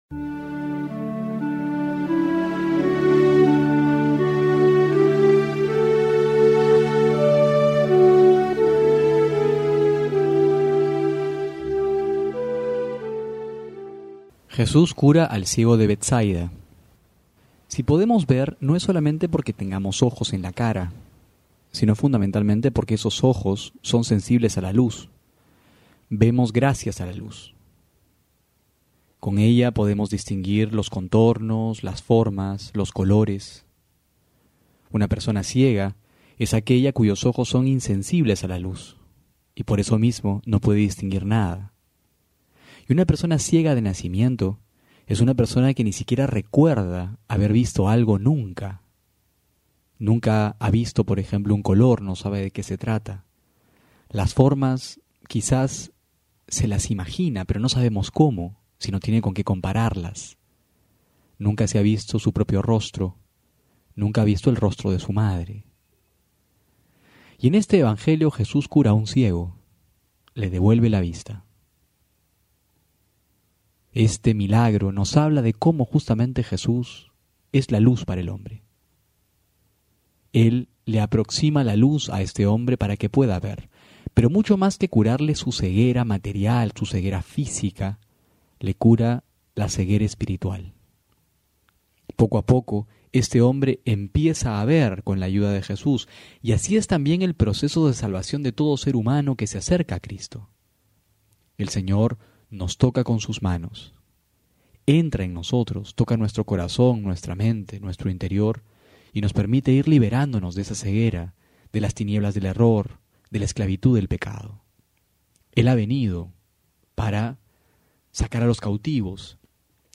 Homilía para hoy: Marcos 8,22-26 Meditación Dominical: Jn 1,35-42 Punto de Vista: La formación moral de los niños Un pensamiento: Podemos sufrir que nos hagan ventaja en otras cosas, pero no en la obediencia.
febrero15-12homilia.mp3